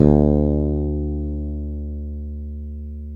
Index of /90_sSampleCDs/Roland L-CD701/BS _E.Bass 3/BS _Ch.Fretless